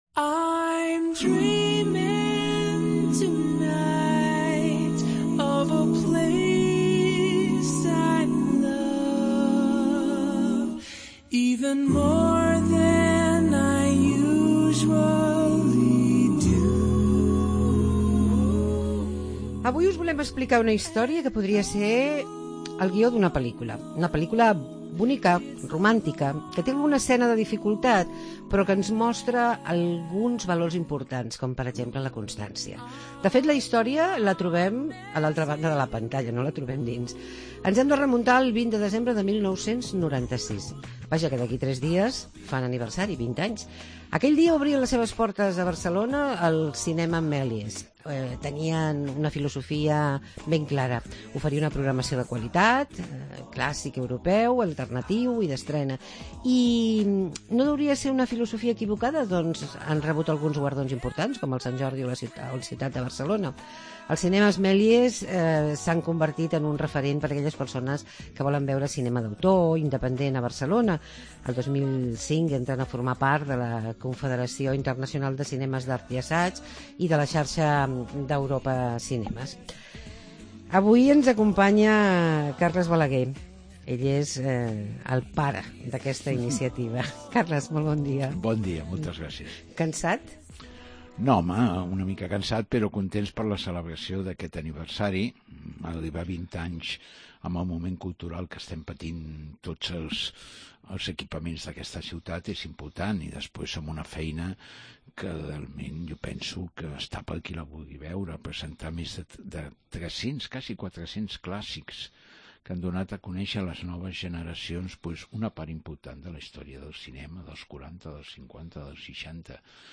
Els cinèmes Mélíès fan 20 anys. Entrevista